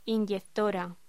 Locución: Inyectora
voz